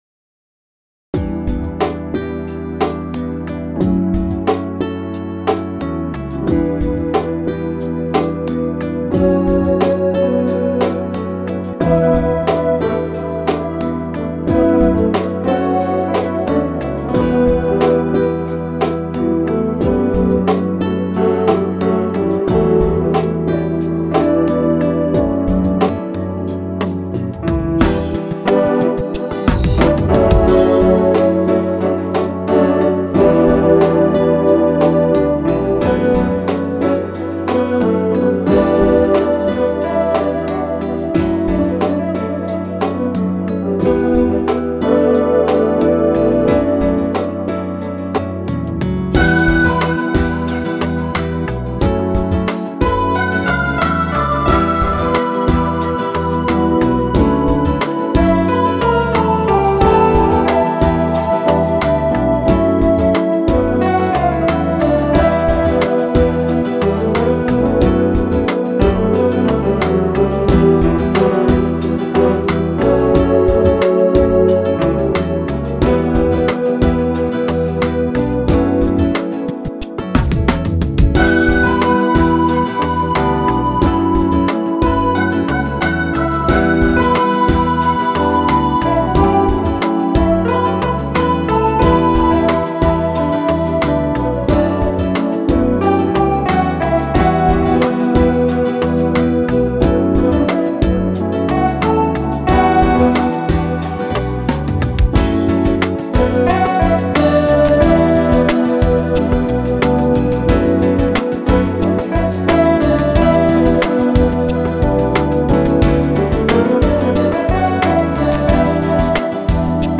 fantasia improvvisata